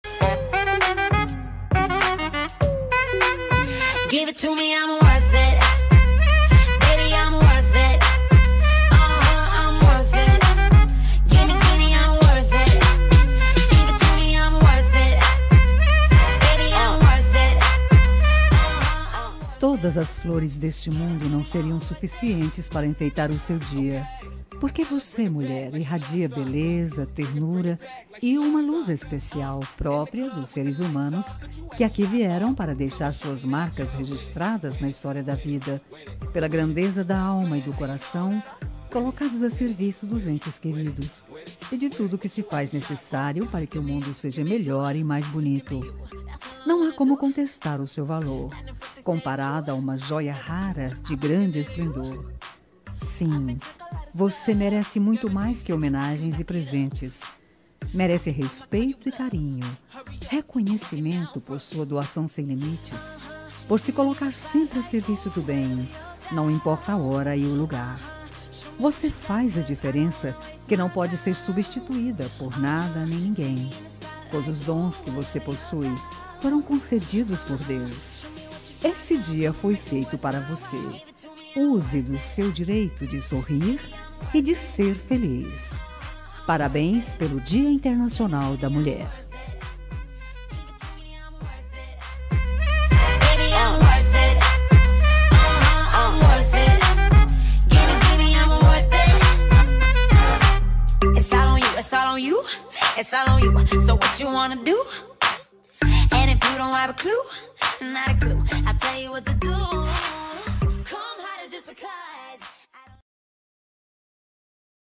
Dia das Mulheres Neutra – Voz Feminina – Cód: 5290